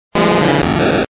Fichier:Cri 0129 DP.ogg